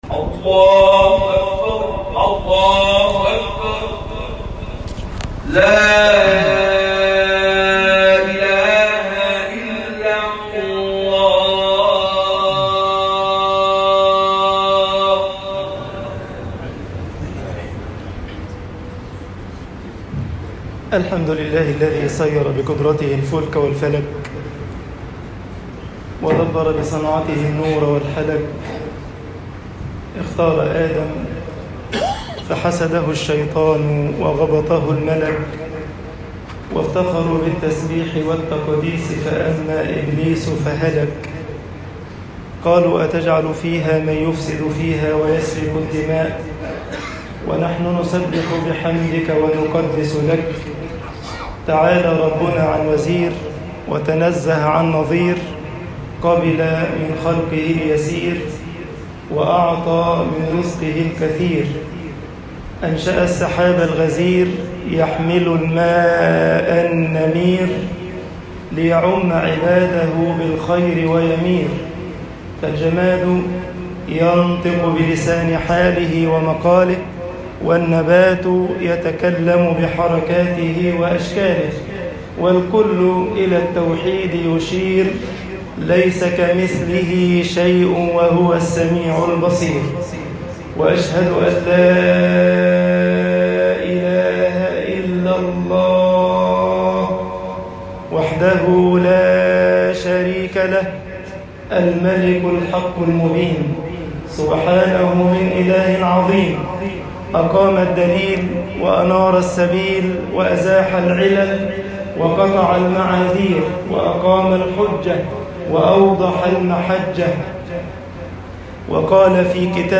خطب الجمعة - مصر العمل دليل العلم طباعة البريد الإلكتروني التفاصيل كتب بواسطة